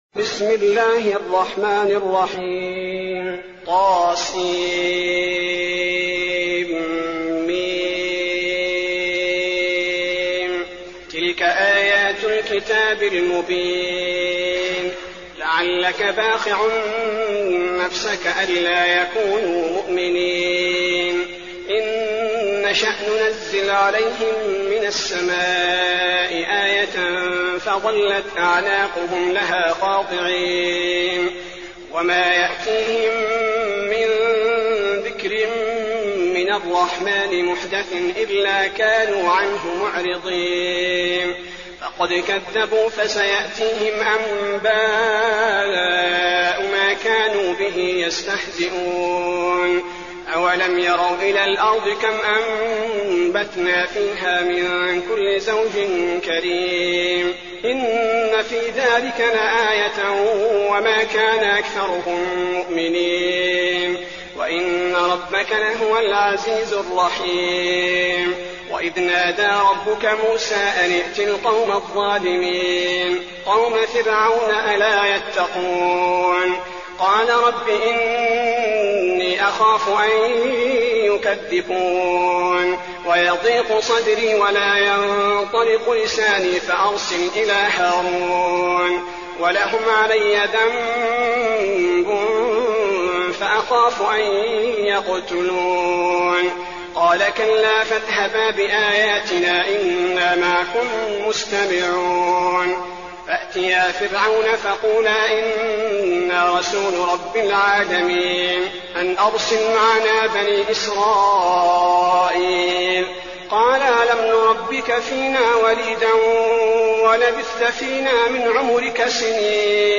المكان: المسجد النبوي الشعراء